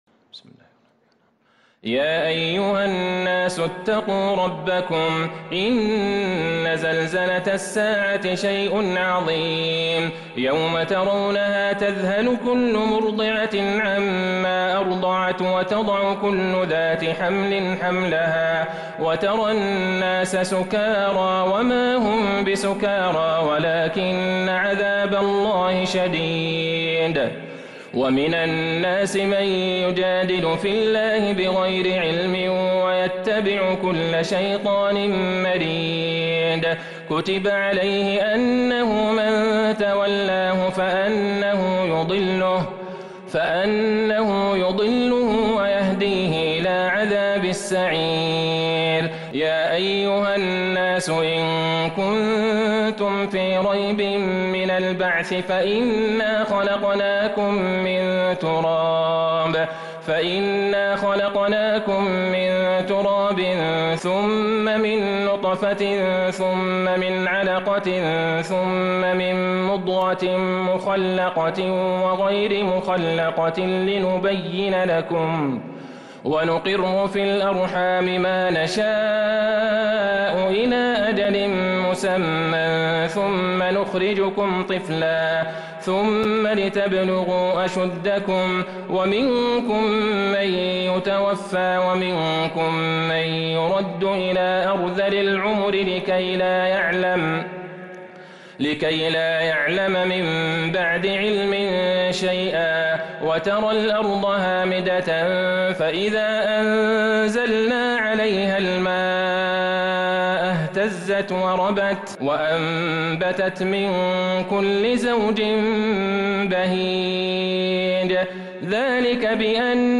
سورة الحج كاملة من تراويح الحرم النبوي 1442هـ > مصحف تراويح الحرم النبوي عام 1442هـ > المصحف - تلاوات الحرمين